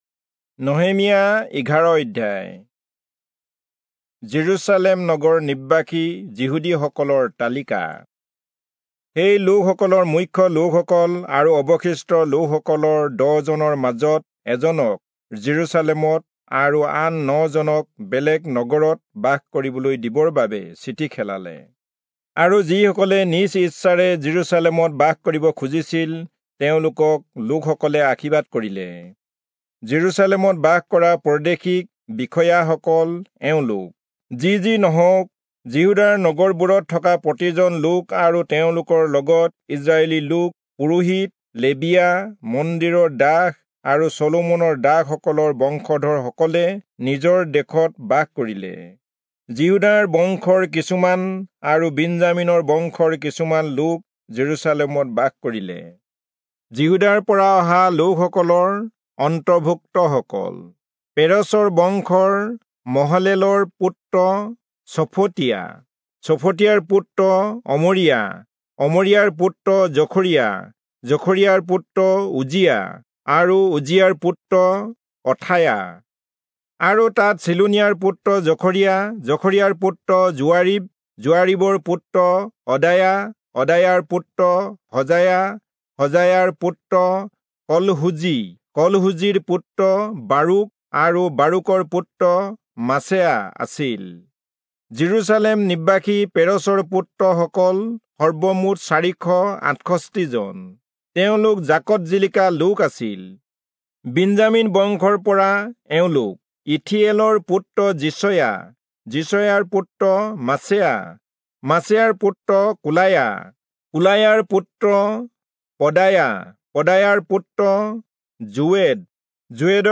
Assamese Audio Bible - Nehemiah 11 in Erven bible version